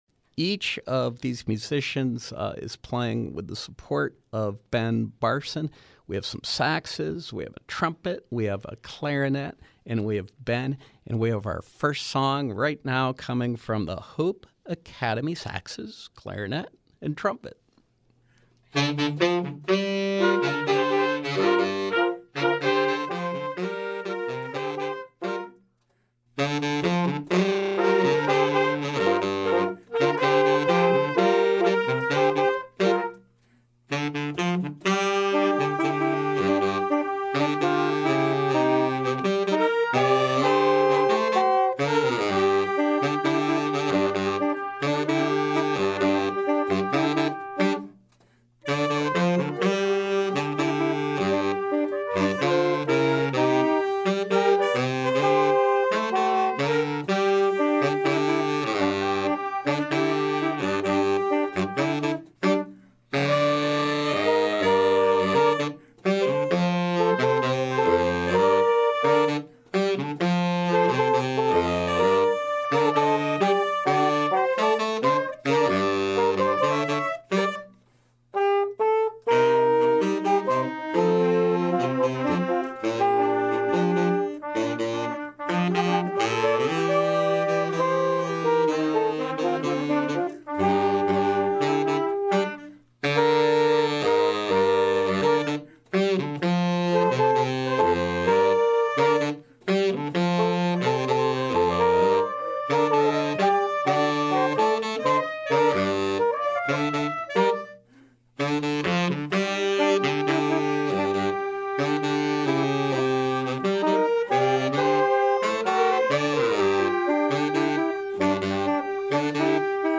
jazz selections